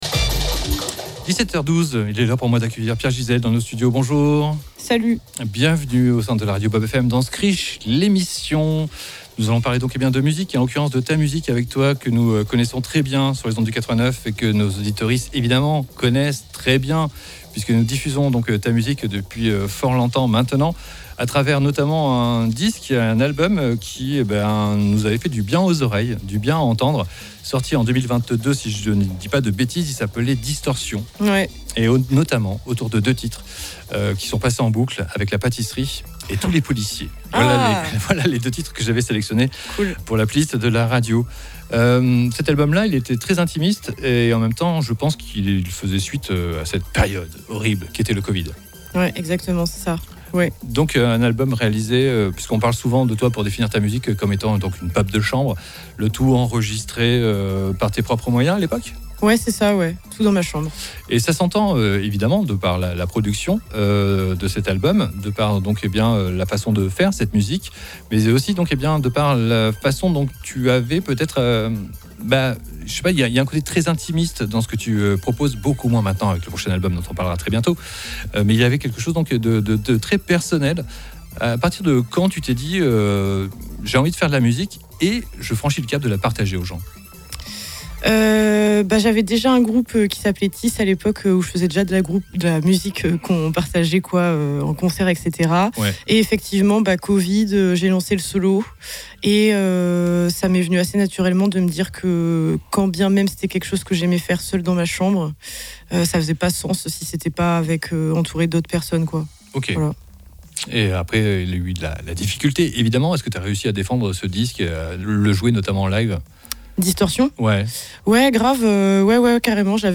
Entretien & live